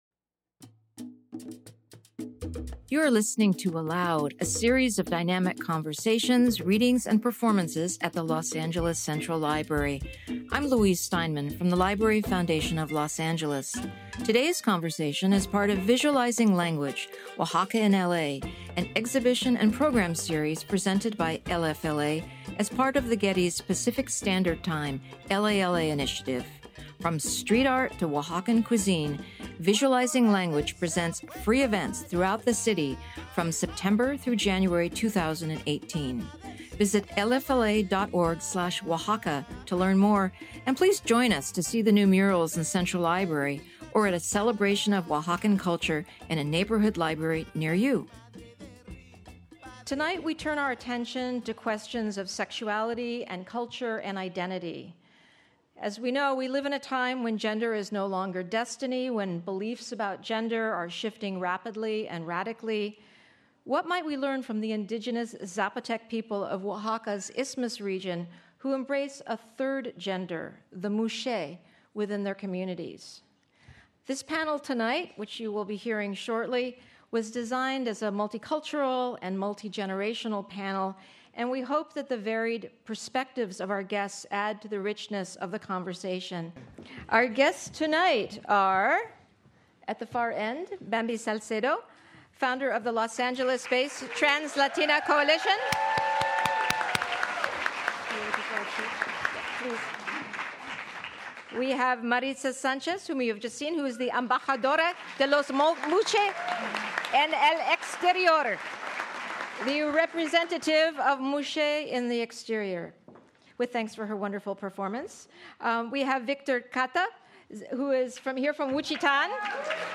Simultaneous interpretation was provided by Antena Los Ángeles.